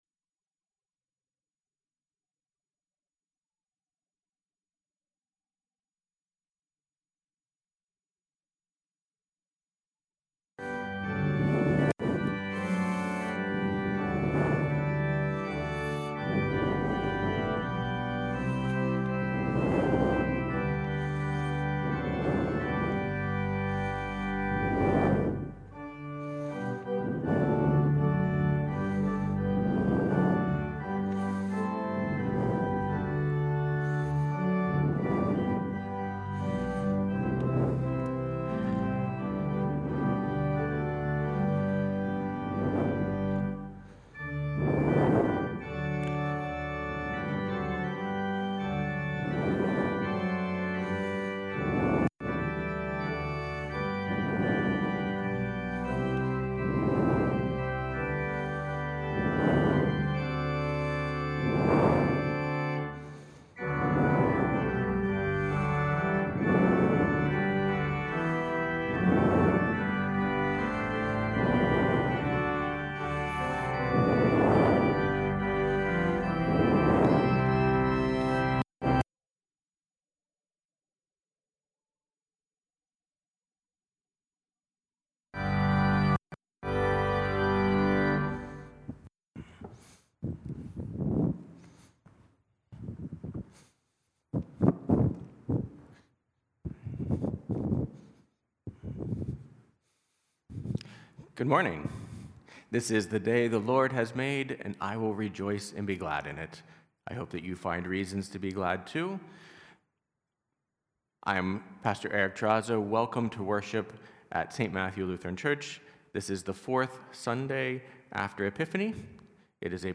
Sermon 4th Sunday after Epiphany